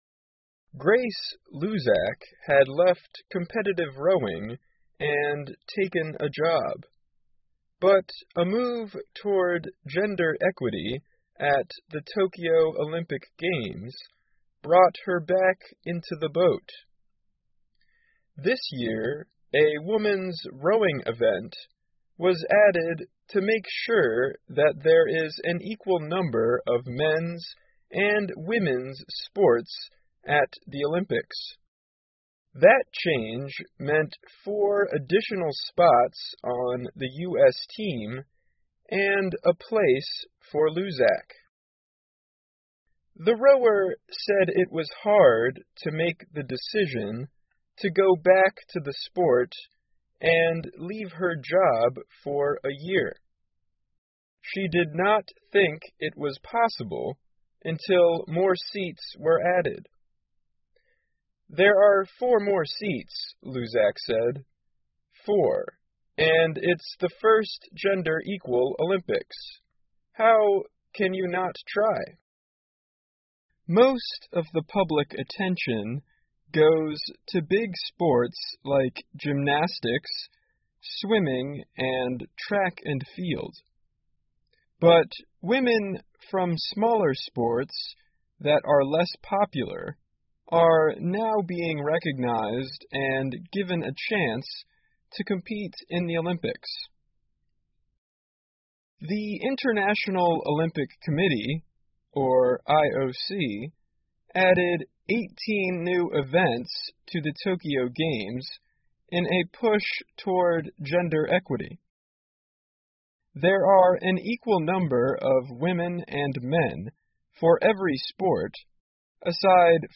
VOA慢速英语--东京奥运会将成为首个男女运动员参与率平等的奥运会 听力文件下载—在线英语听力室